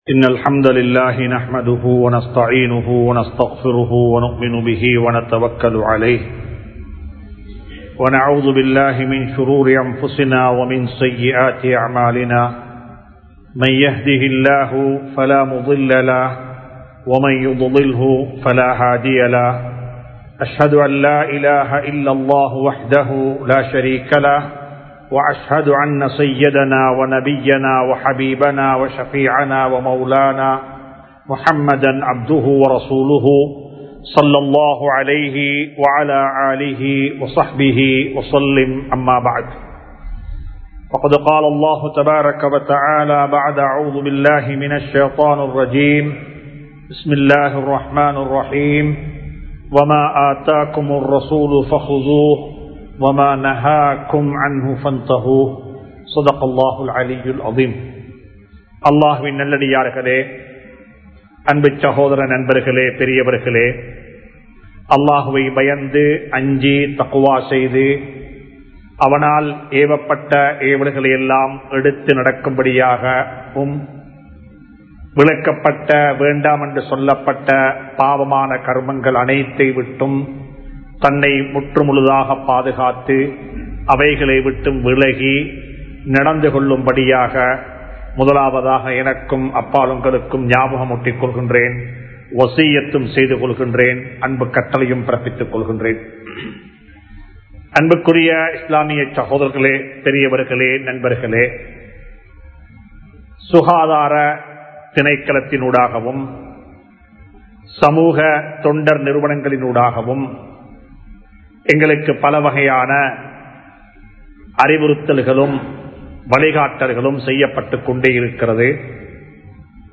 இழந்தால் பெறமுடியாத ஆரோக்கியம் | Audio Bayans | All Ceylon Muslim Youth Community | Addalaichenai